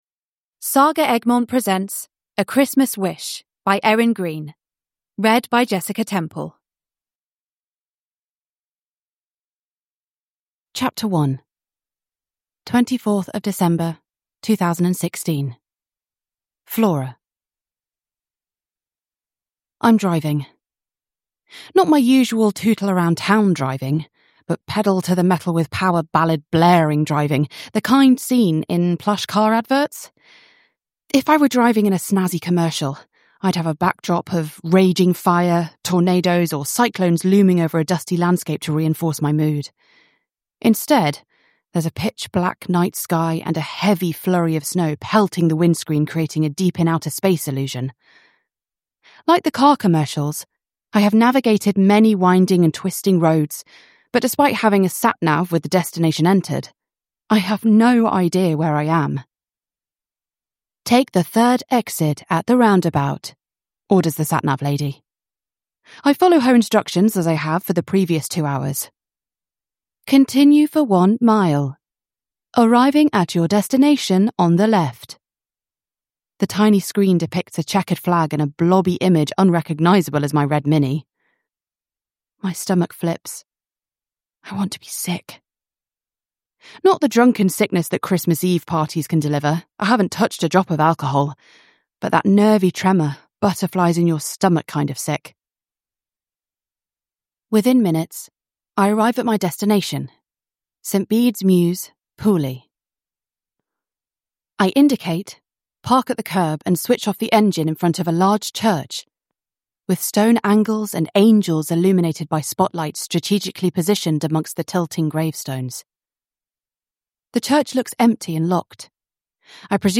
A Christmas Wish: A cosy Christmas story with an emotional journey of self discovery – Ljudbok